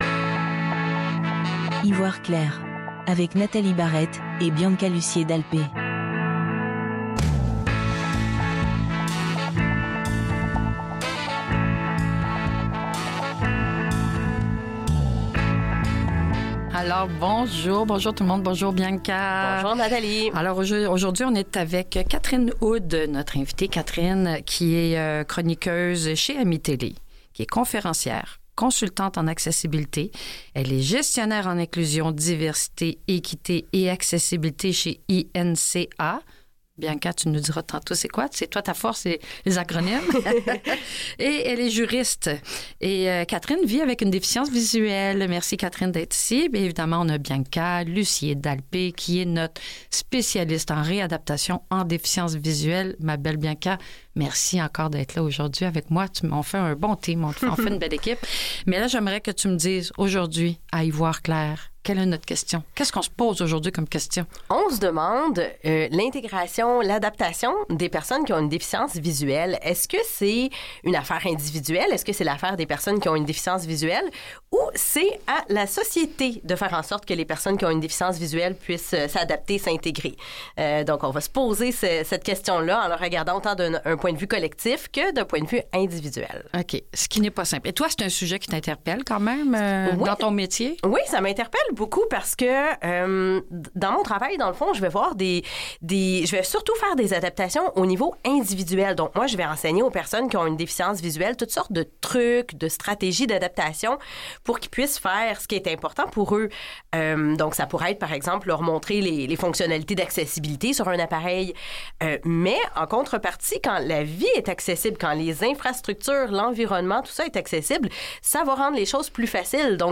Dans cet épisode enrichissant et essentiel, nos animatrices explorent un enjeu clé : l'intégration des personnes vivant avec une déficience visuelle est-elle une responsabilité individuelle ou collective?